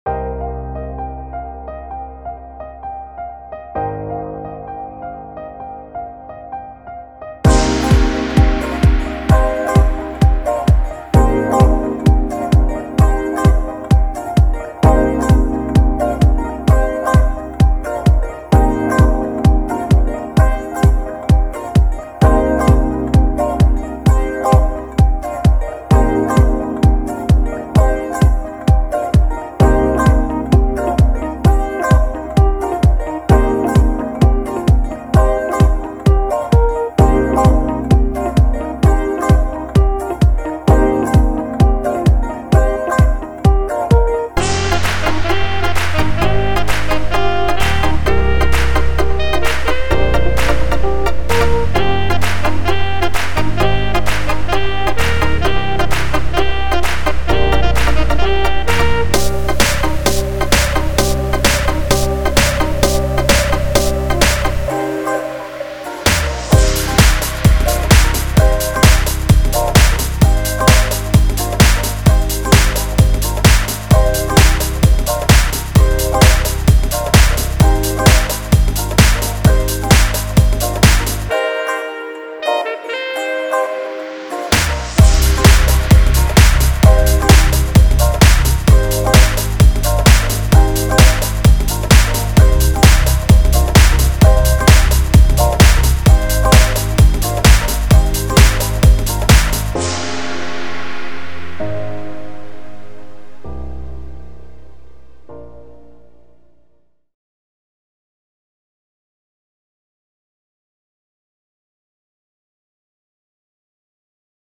beaty